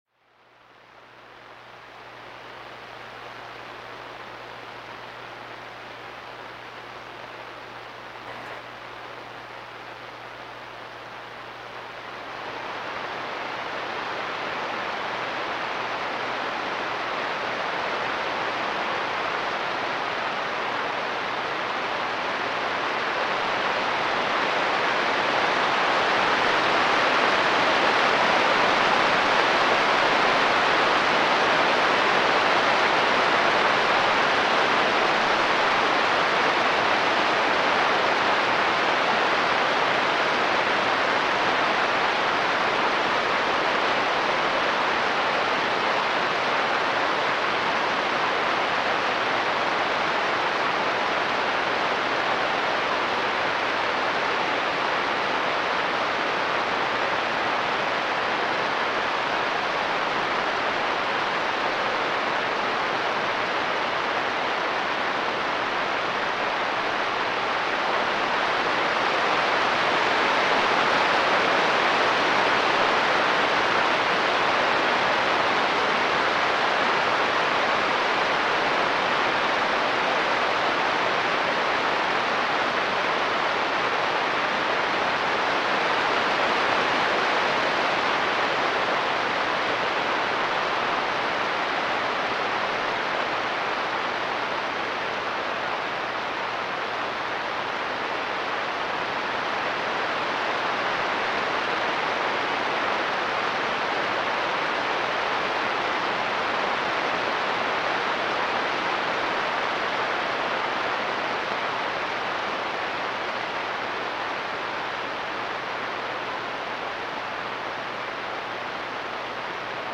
Below are some specimens captured on our decametric radio telescope array here in New Mexico.
Channel A: 24 MHz Channel B : 21.1 MHz